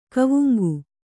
♪ kavuŋgu